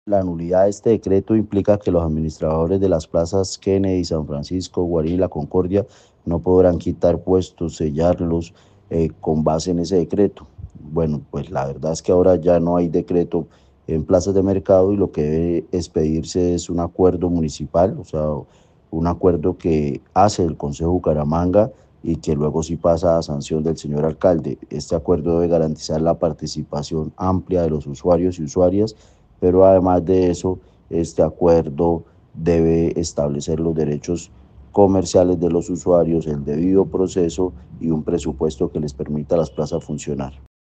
Jorge Flórez, concejal de Bucaramanga